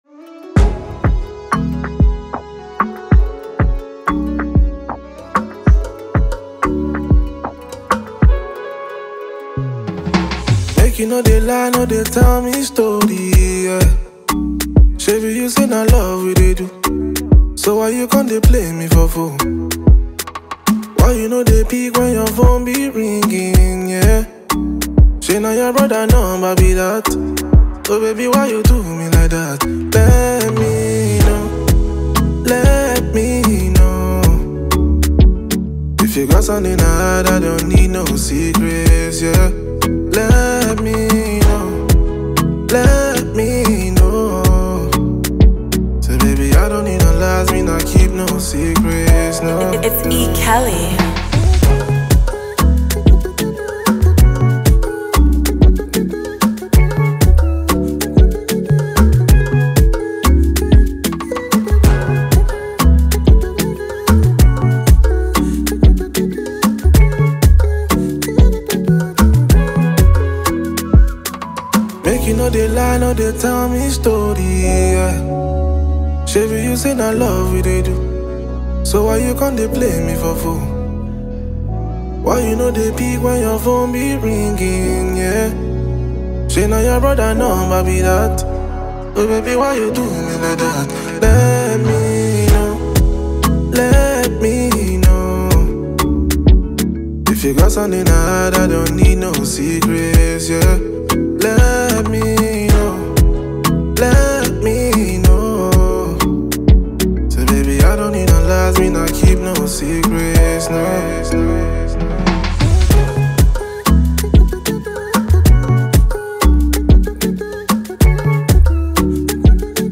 Afro-Caribbean and dancehall